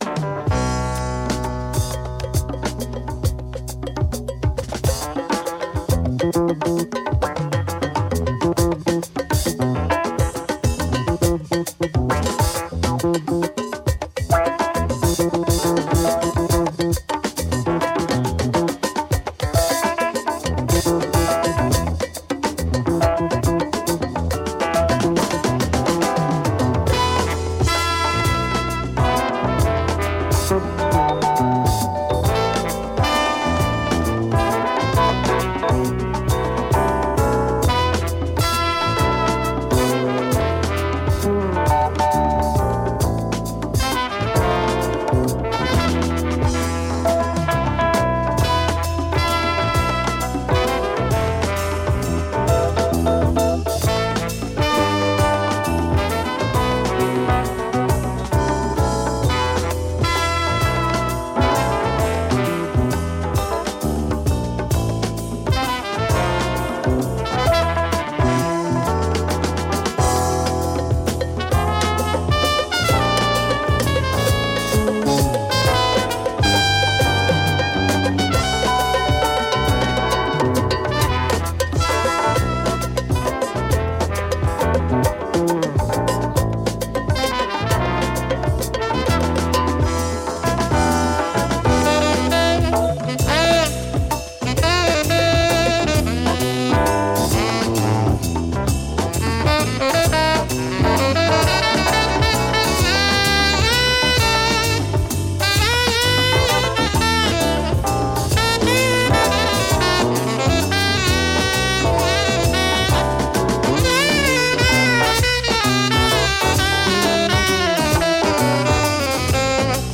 Beautiful spiritual jazz exotica